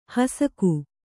♪ hasaku